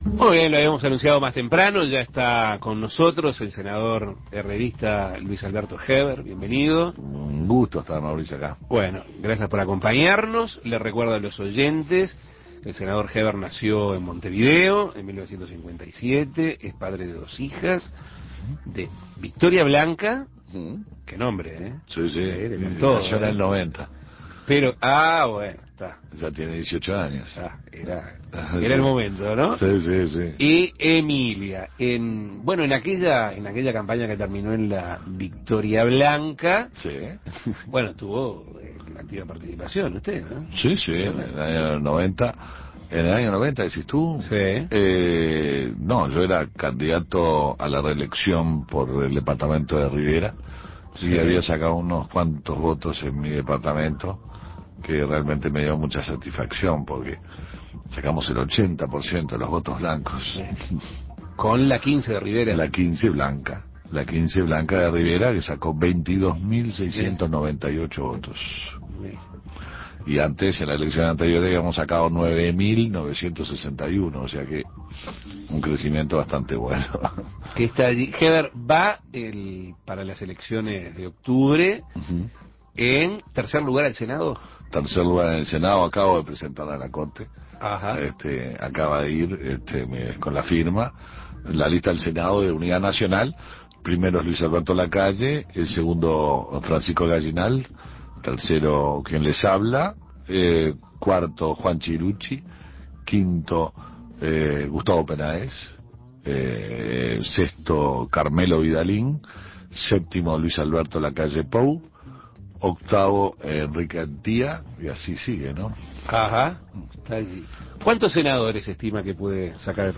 El senador herrerista Luis Alberto Heber fue entrevistado en Asuntos Pendientes, en donde habló, entre otras cosas, de las próximas elecciones. Heber hizo pronósticos sobre lo que cree que sucederá y habló de la actual administración.